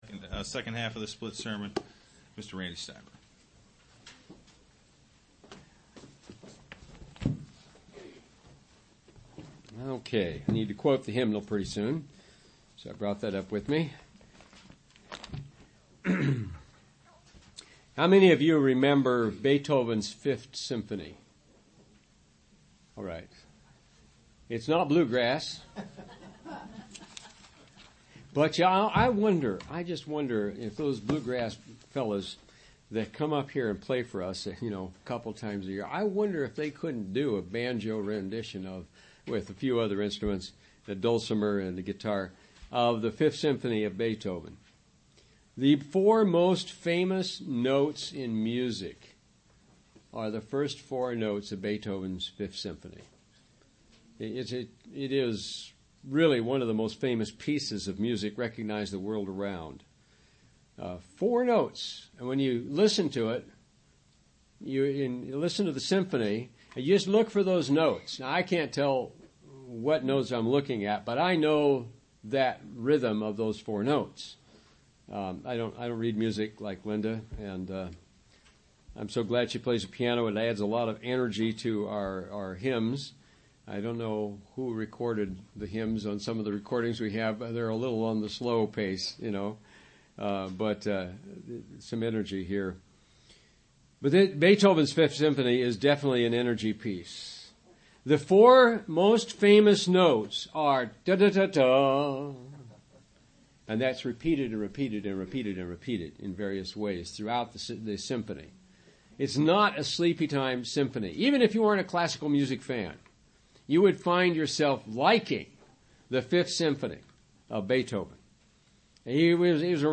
Given in Wheeling, WV
UCG Sermon Studying the bible?